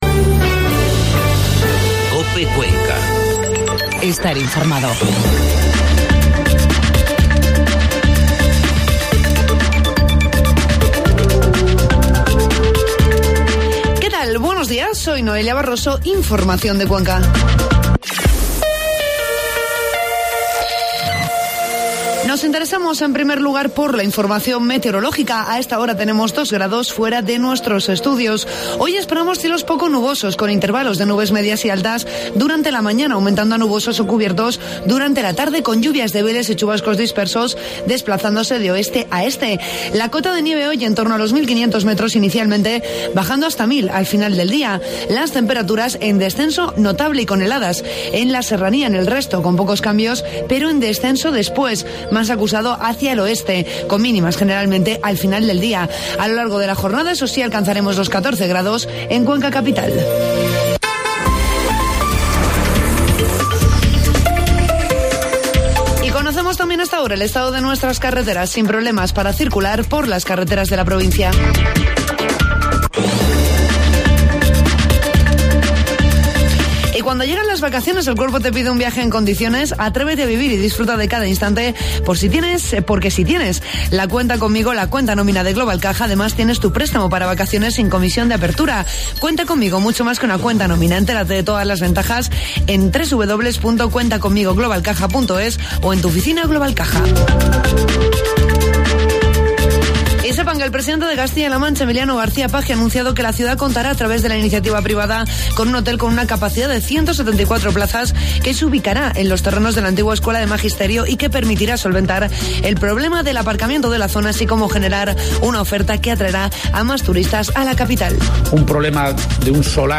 Informativo matinal COPE Cuenca 25 de enero